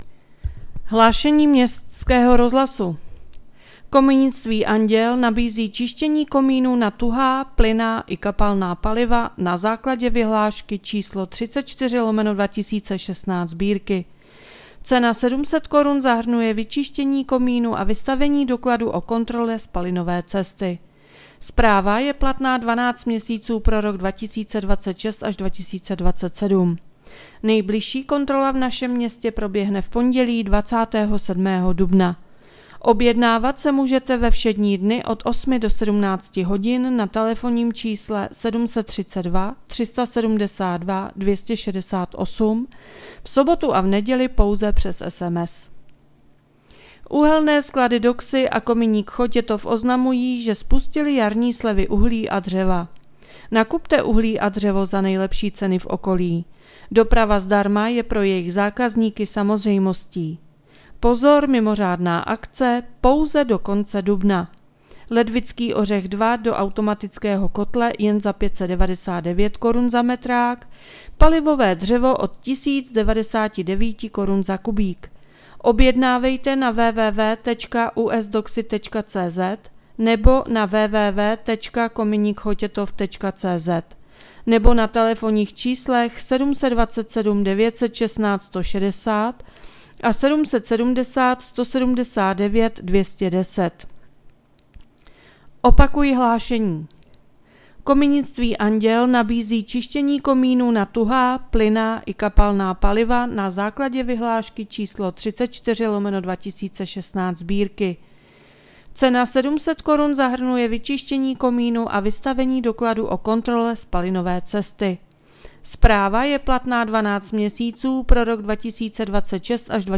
Hlášení městského rozhlasu 22.4.2026